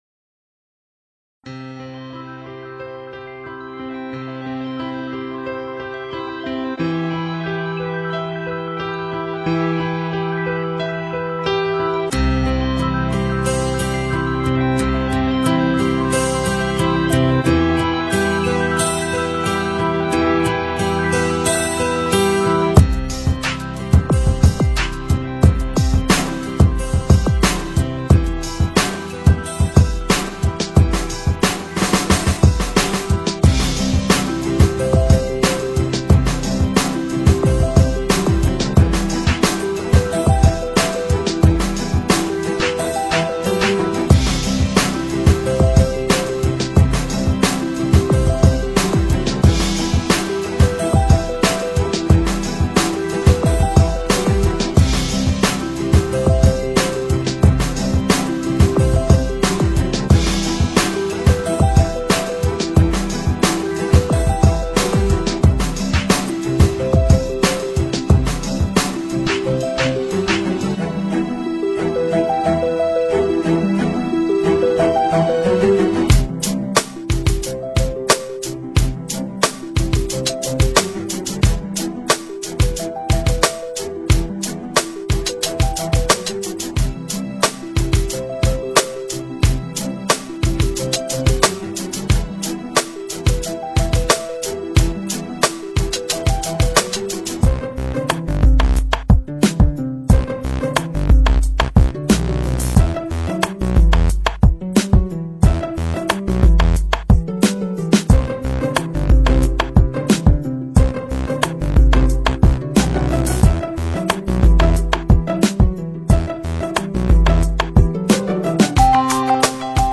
аудио медитация [1]
Скачайте эту мелодию и прослушайте до конца,поскольку она разнообразная но может быть и успокаивающей!